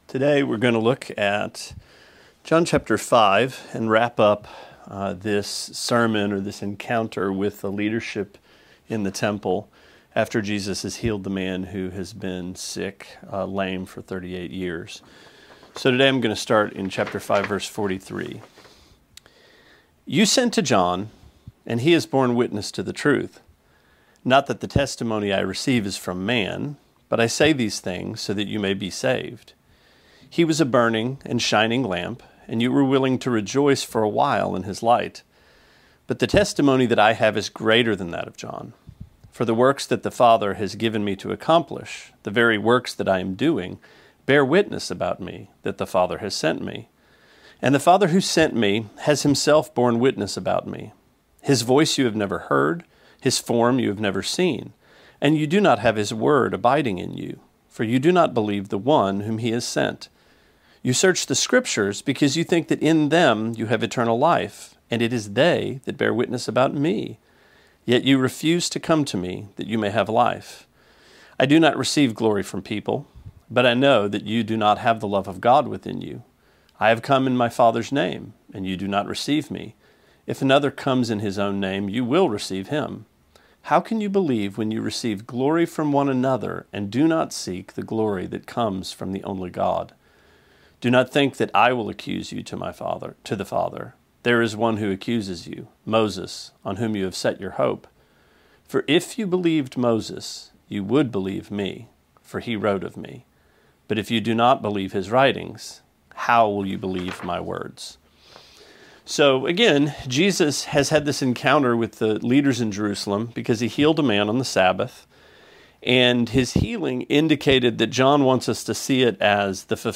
Sermonette 4/30: John 5:36-47: Bibleolatry